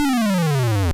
The "Bowser Fall" sound effect heard in Super Mario Bros.
SMB_Bowser_Fall.oga